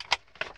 sfxclose.ogg